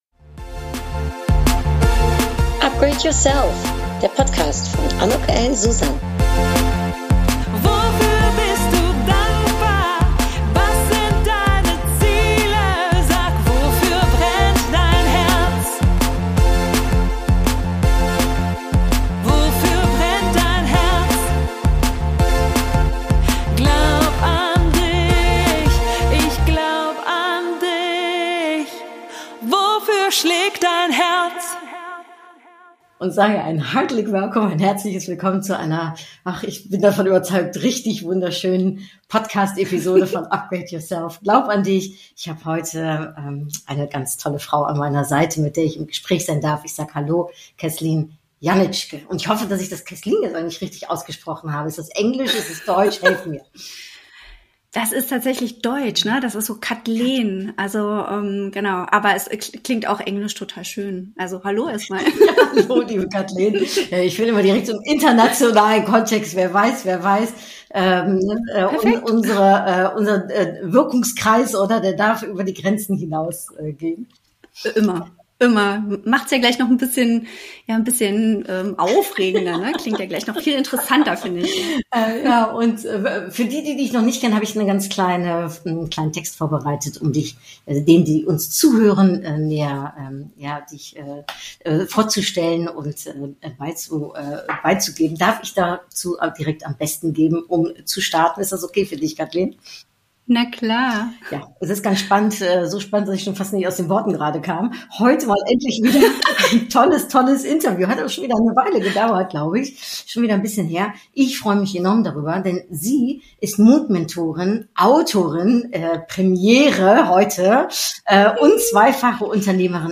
Buch schreiben… höre mal rein, du wirst wie wir darüber lachen können.